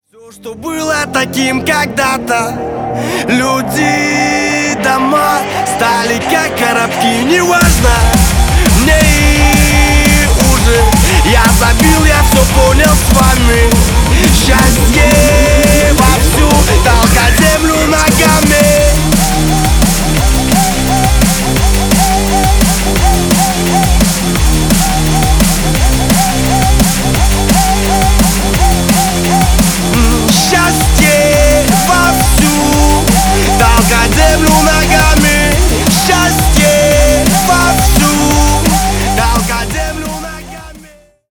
• Качество: 320 kbps, Stereo
Рок Металл